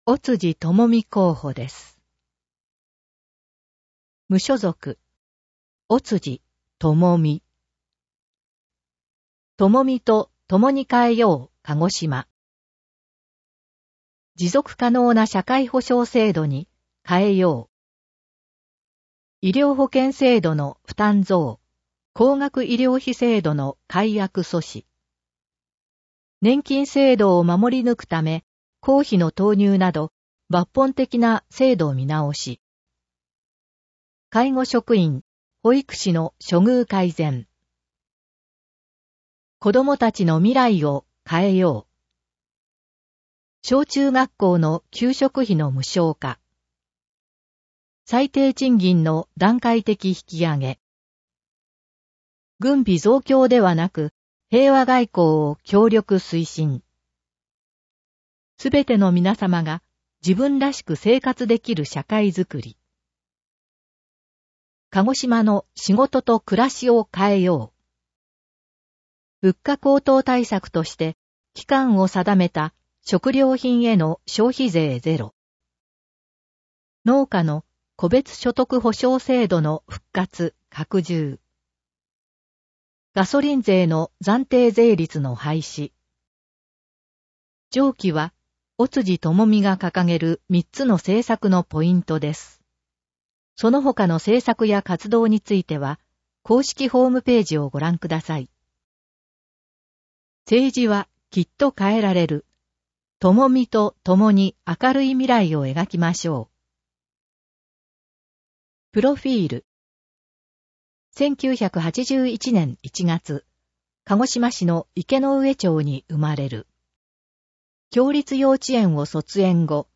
音声読み上げ対応データ（MP3：728KB）